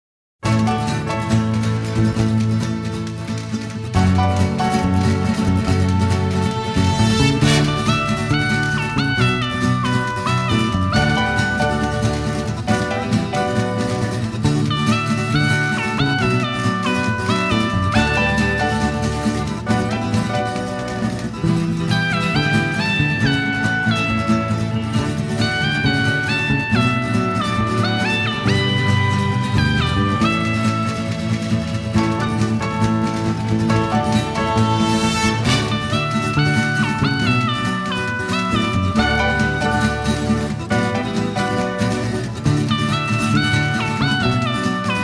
remixed and mastered from the original 1/2" stereo tapes.